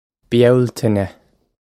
Byal-tin-eh
This is an approximate phonetic pronunciation of the phrase.